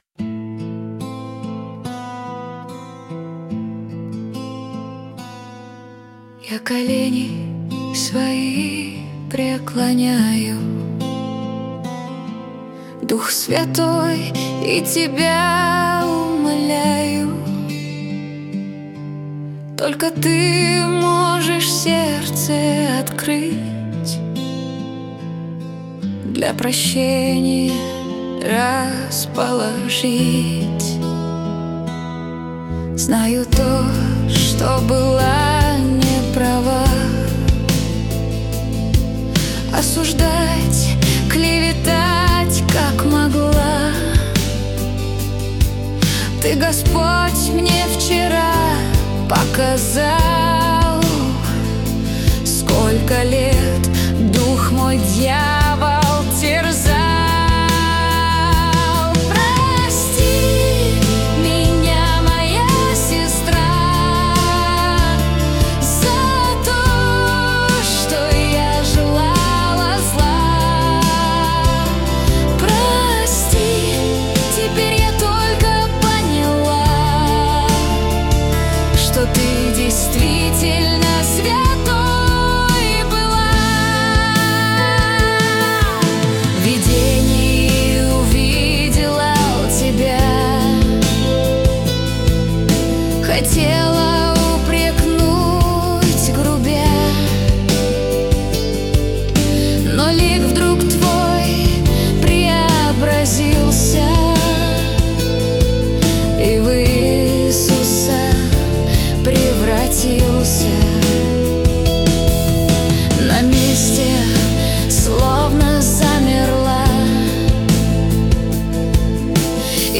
песня ai
271 просмотр 663 прослушивания 56 скачиваний BPM: 73